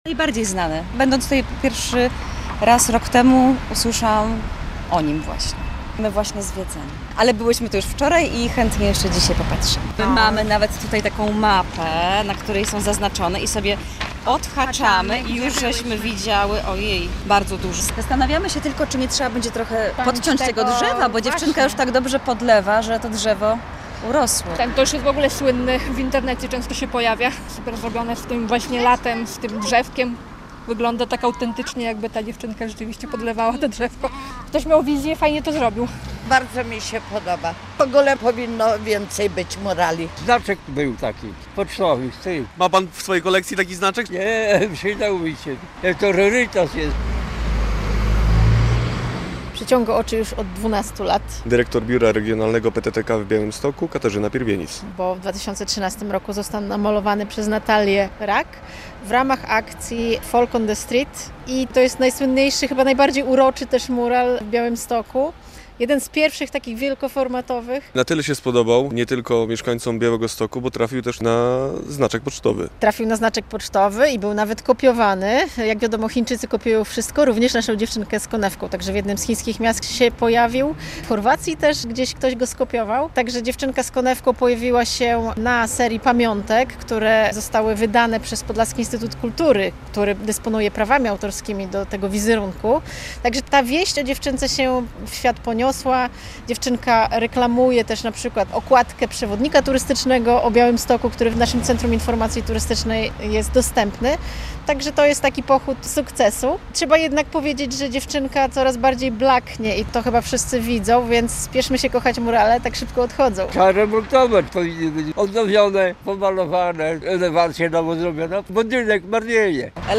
Dziewczynka z konewką - relacja